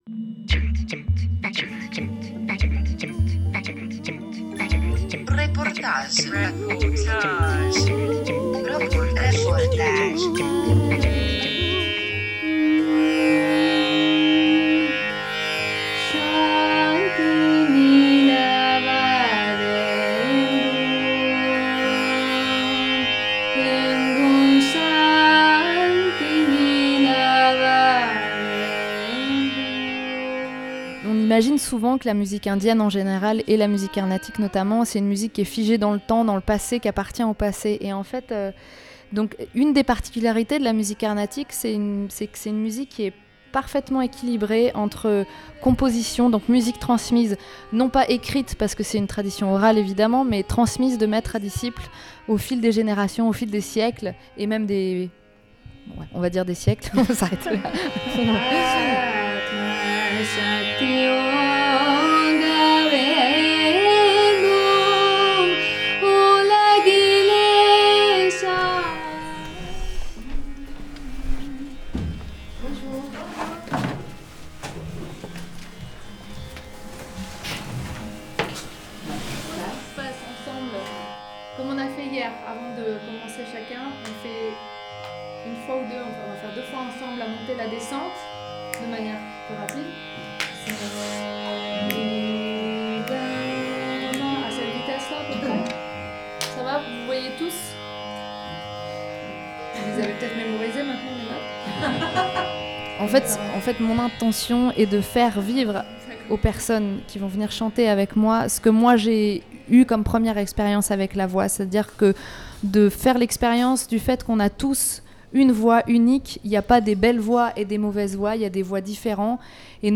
21 juin 2021 11:50 | Interview, reportage
L’atelier “Le réveil de la voix” s’est tenu dans le cadre de l’édition 2020 du festival Éclats, à Dieulefit.
Cet atelier s’est étalé sur trois jours, à la Chapelle Saint-Maurice. Il fut l’occasion pour les participants de s’initier aux spécificités de cette musique indienne, mais aussi, et surtout, d’apprendre à découvrir leur voix.